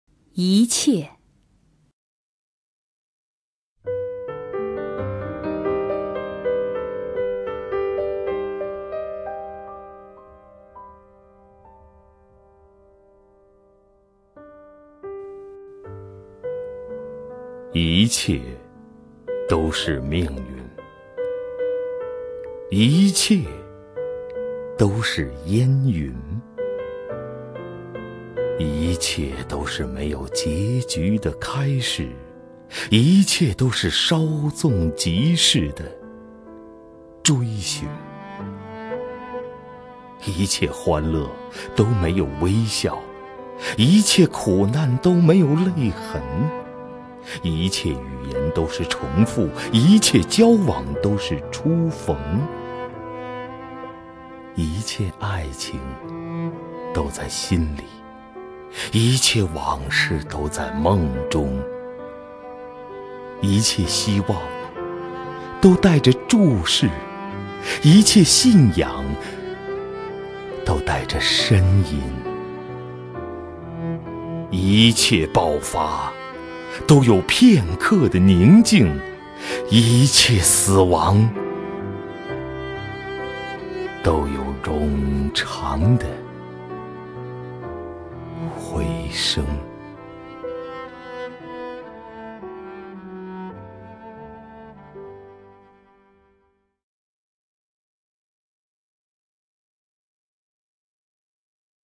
首页 视听 名家朗诵欣赏 赵屹鸥
赵屹鸥朗诵：《一切》(北岛)
YiQie_BeiDao(ZhaoYiOu).mp3